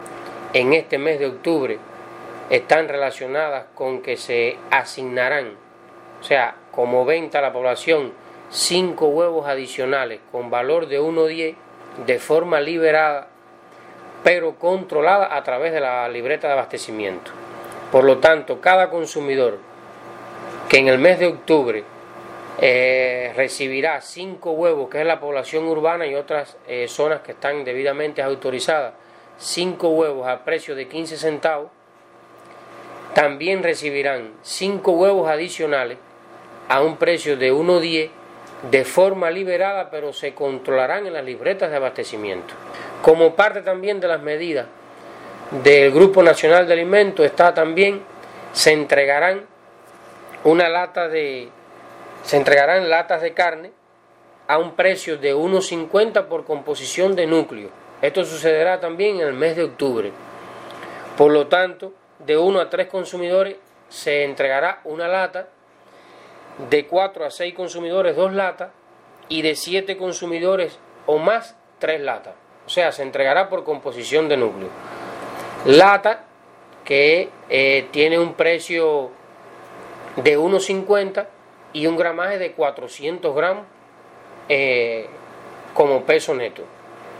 Declaraciones-Harold-P--rez-vicepresidente-del-Consejo-de-la-Administracion-Provincial-1.mp3